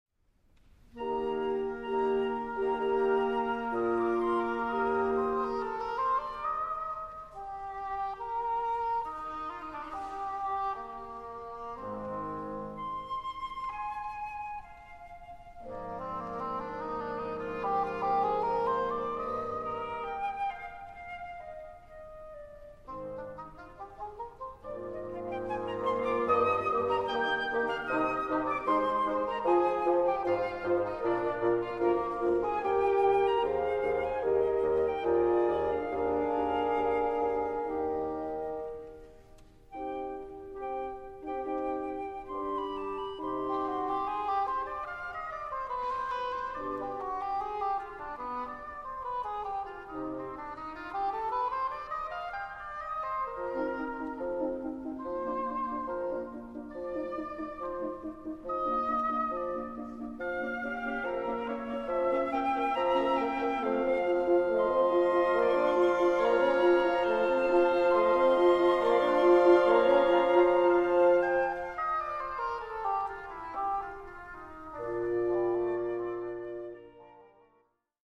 in E flat major
Here his playing was warm and abundantly satisfying.